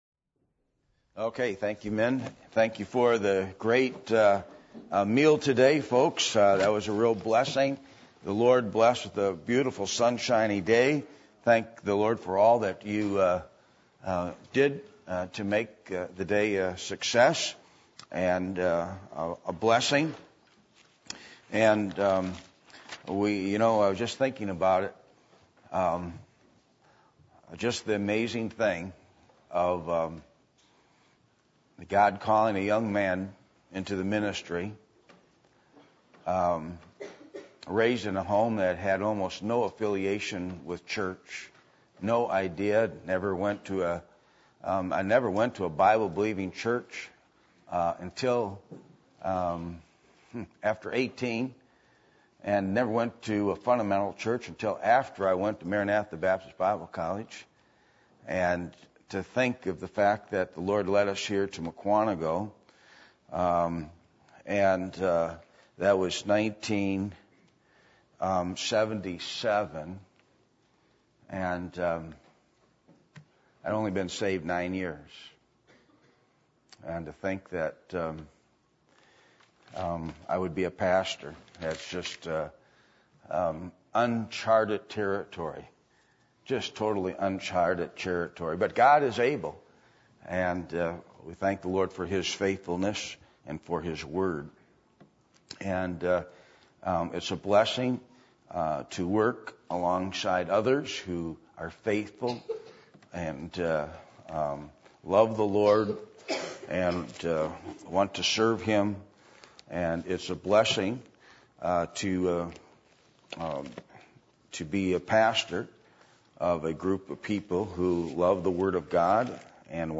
Passage: Joshua 1:12-18 Service Type: Sunday Evening %todo_render% « Jesus